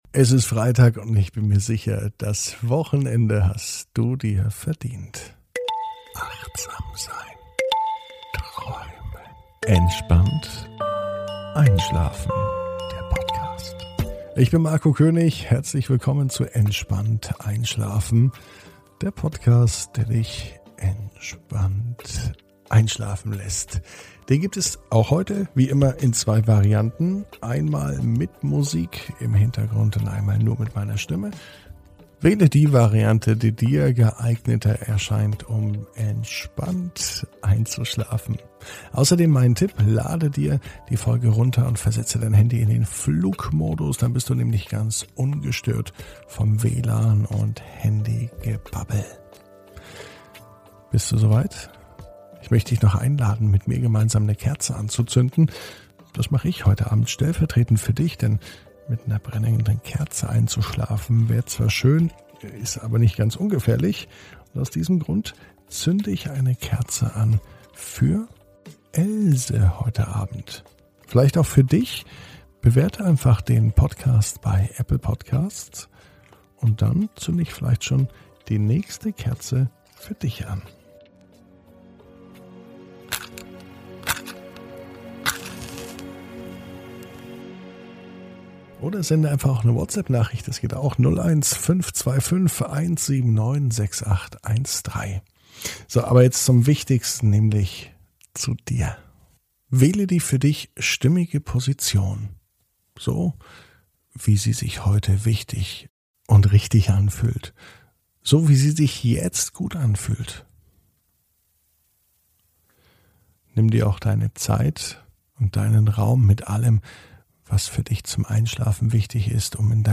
(ohne Musik) Entspannt einschlafen am Freitag, 11.06.21 ~ Entspannt einschlafen - Meditation & Achtsamkeit für die Nacht Podcast